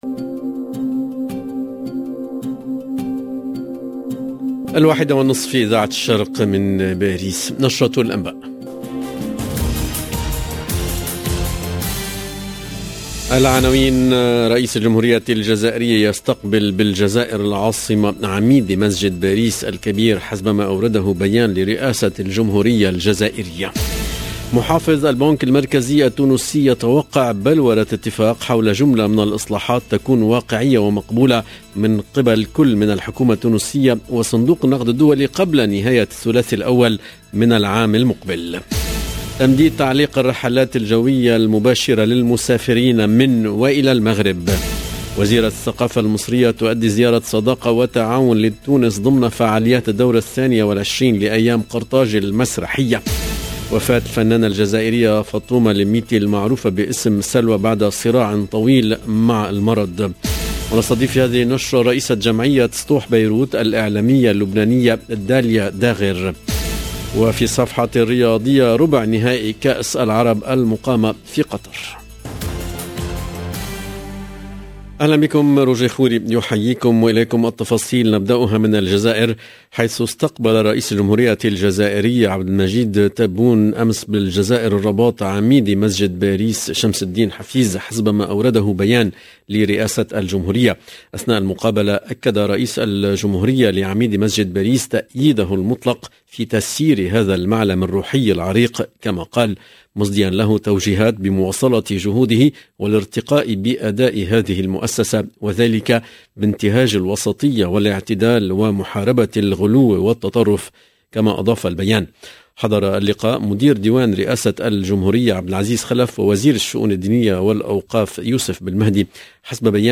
LE JOURNAL EN ARABE DE LA MI-JOURNEE DU 10/12/21